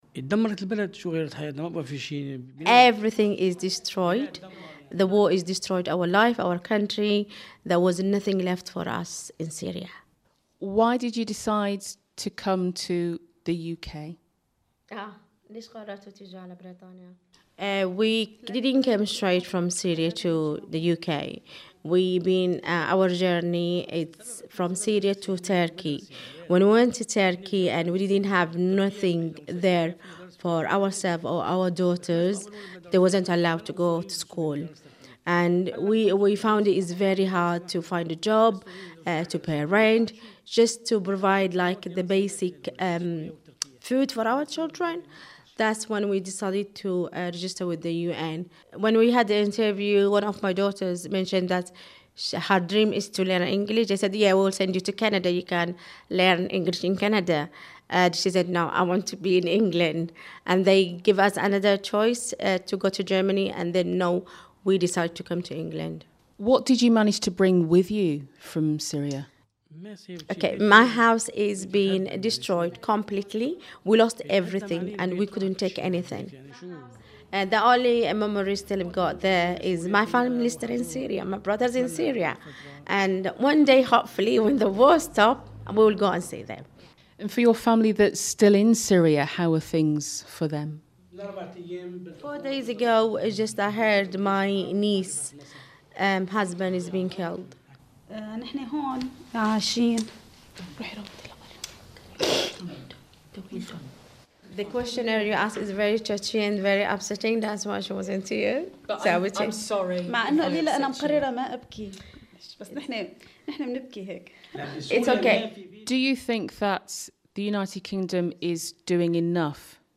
Their answers have been translated by an interpreter.